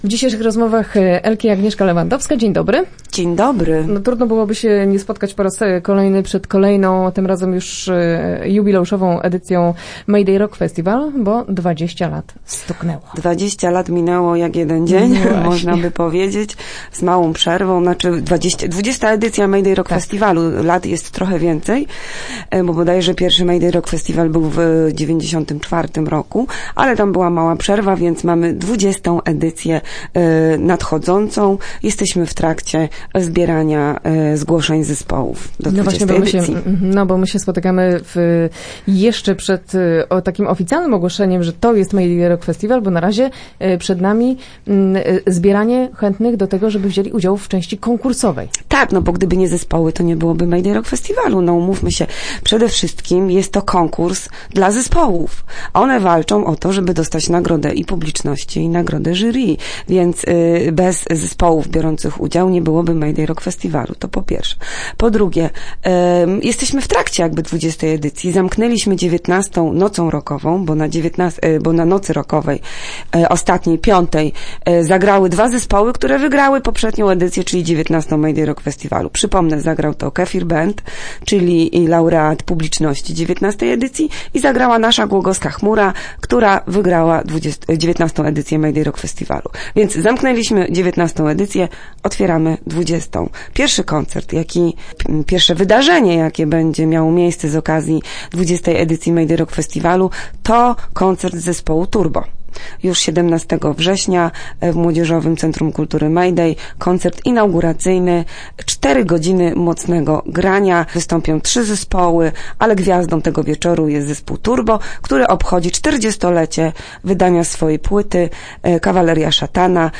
Start arrow Rozmowy Elki arrow Czekają na zgłoszenia